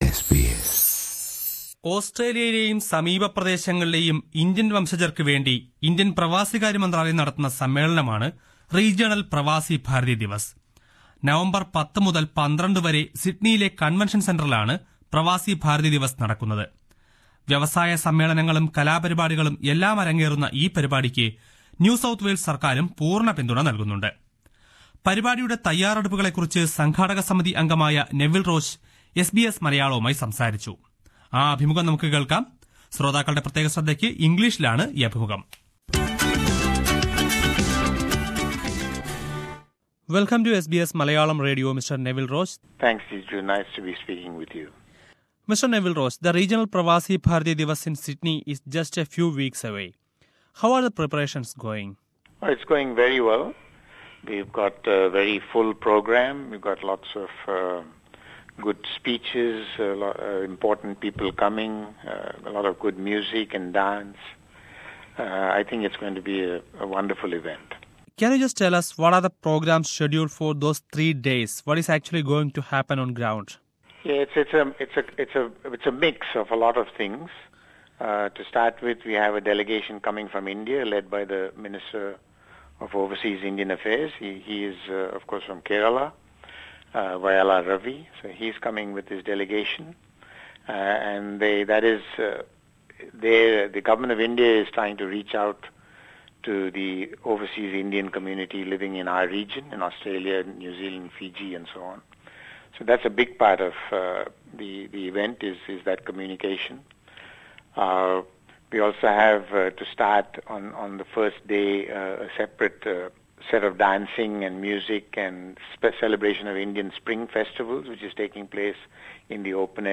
Here is an interview with…